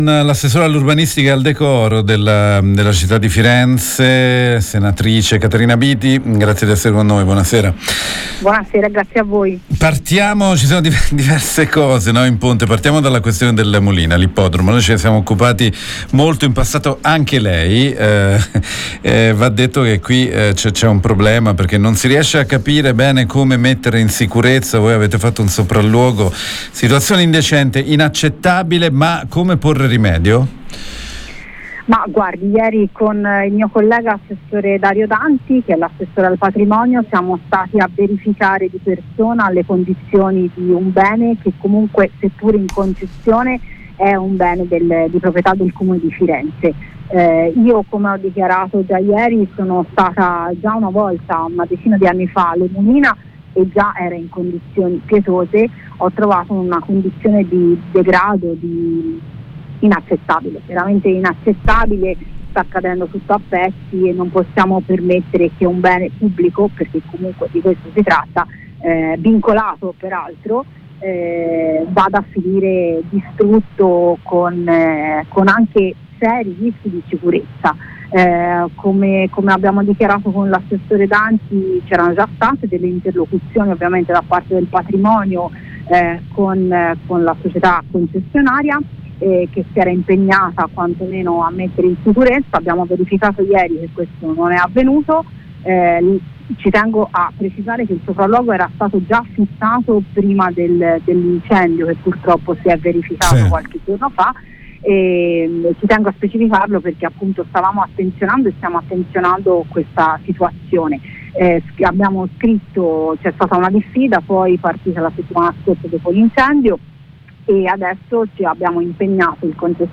Ne abbiamo parlato con l’assessora all’urbanistica (e al degrado) del comune di Firenze, Caterina Biti